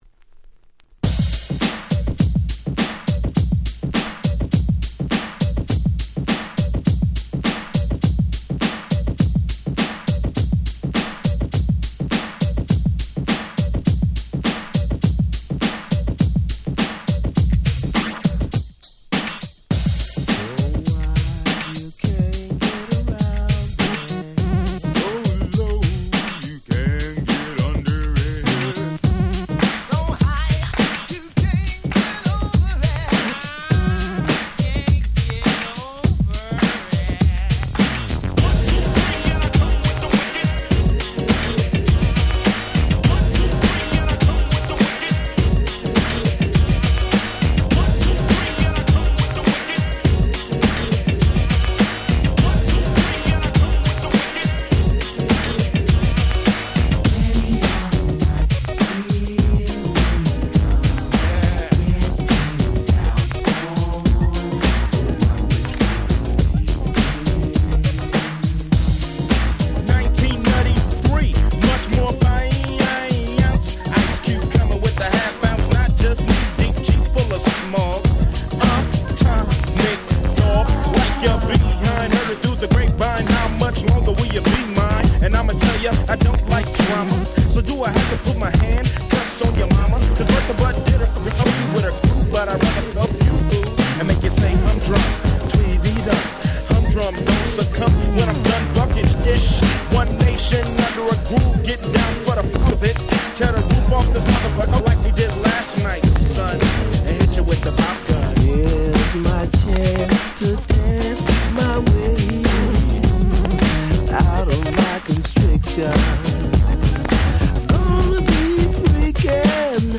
【Media】Vinyl 12'' Single
【Condition】B (スリキズあり。A-1に音に出る傷あり。)